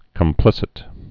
(kəm-plĭsĭt)